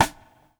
stick.wav